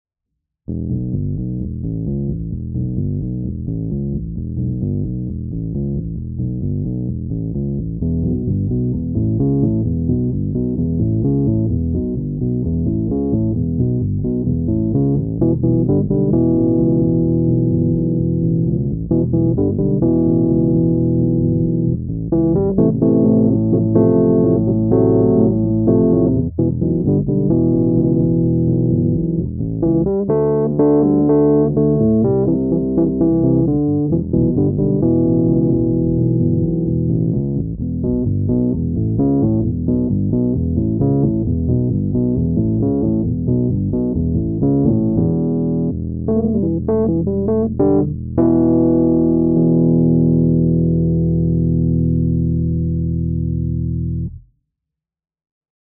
This is a Rhodes Piano Bass from late 1974 with golden tine blocks–one of our very favorite production periods for warm & smooth bass tone.
“Obligatory Doors Sample:”
74-Fiesta-Piano-Bass-doors.mp3